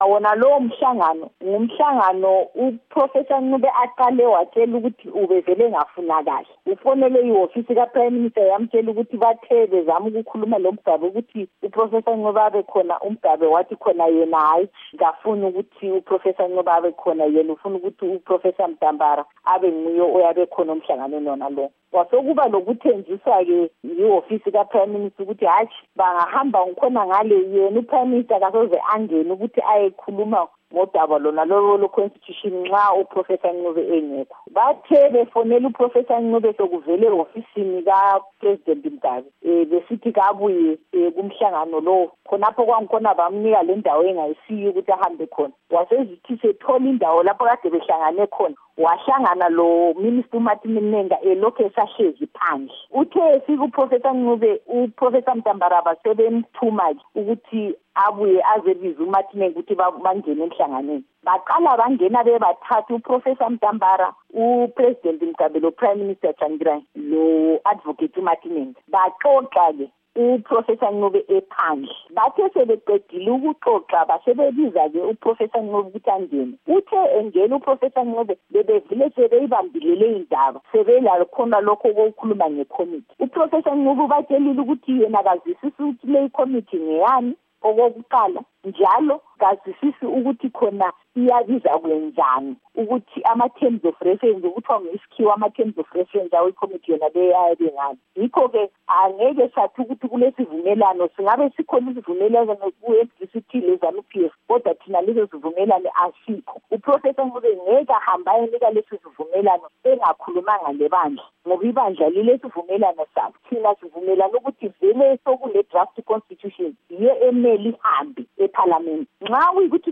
Ingxoxo loNkosikazi Priscillar Misihairambwi-Mushonga